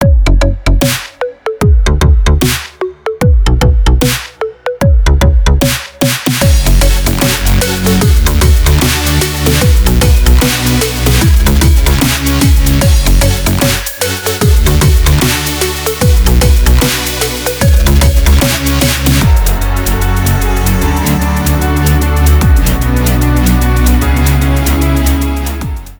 Электроника
громкие # без слов